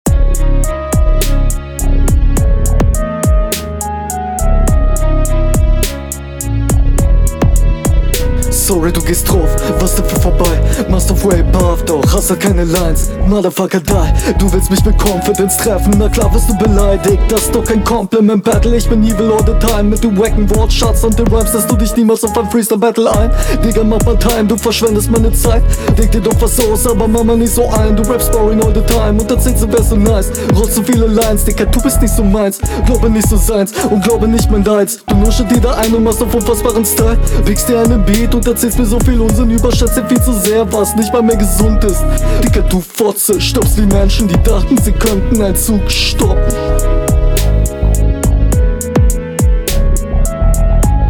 Leider bist du hier deinem Gegner unterlegen und auch öfter nicht im Takt.
"du gehst druff" is crazy bro. sehr unverständlich und unsicher gerappt. teils sehr am takt …